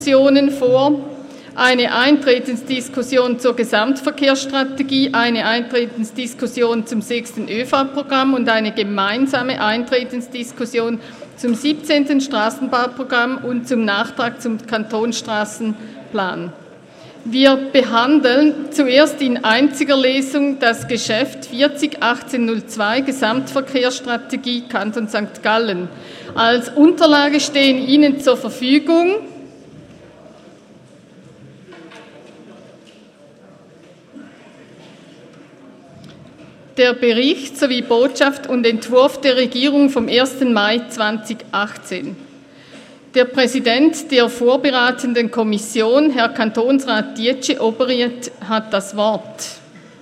18.9.2018Wortmeldung
Session des Kantonsrates vom 17. bis 19. September 2018